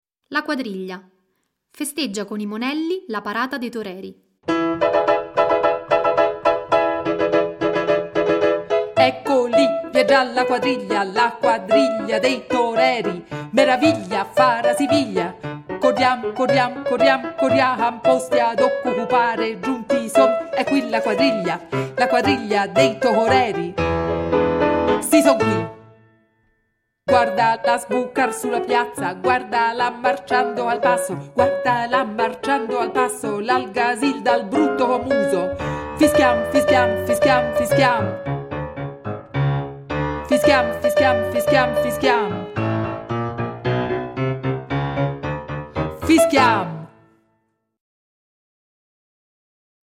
Le carte – Base musicale